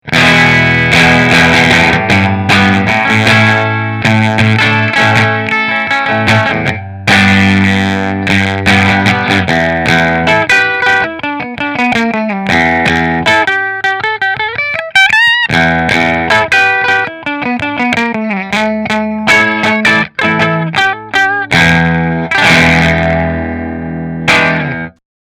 Back is made of Sassafrass which is similar to alder in tone.
No shrill high end. A real smooth guitar!
Rahan Guitars RP Single Cutaway Ambrosia Position 3 Through Fender